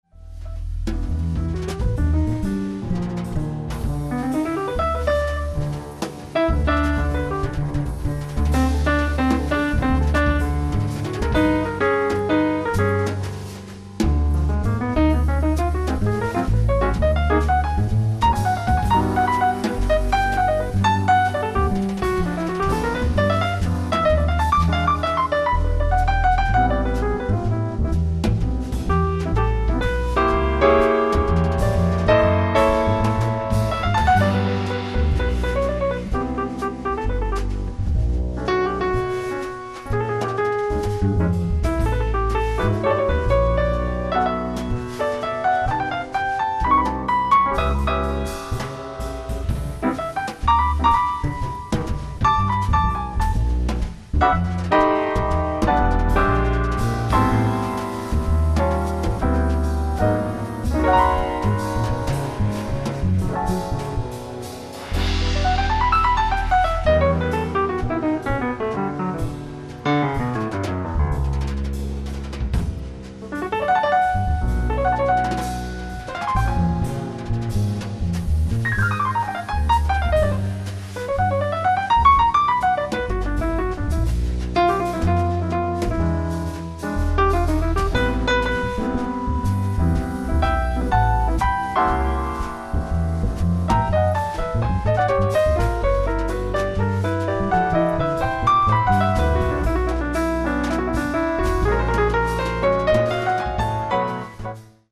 �z���w�E�p���h(sax, fl